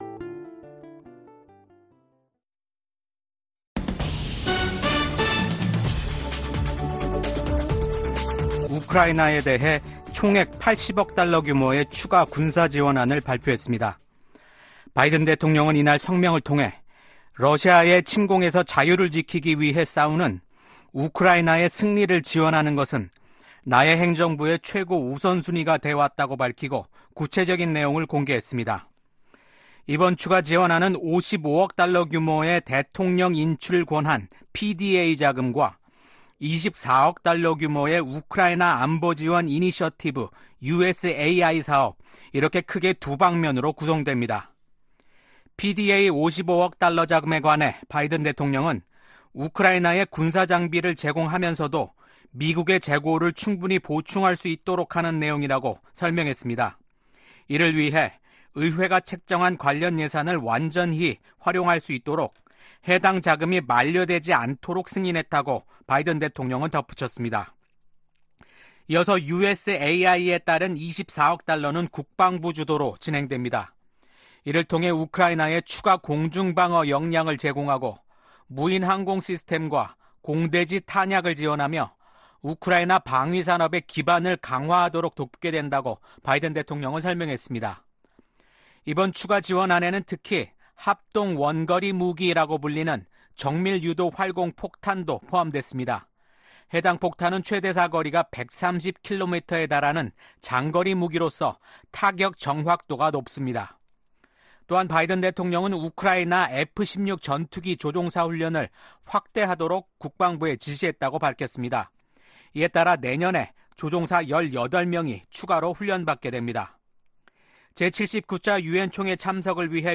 VOA 한국어 아침 뉴스 프로그램 '워싱턴 뉴스 광장' 2024년 9월 27일 방송입니다. 미한일 3국이 외교장관 회의를 개최하고 ‘정치적 전환기’ 속 변함 없는 공조 의지를 확인했습니다. 미국 정부는 북한의 7차 핵실험이 정치적 결정만 남은 것으로 평가한다고 밝혔습니다.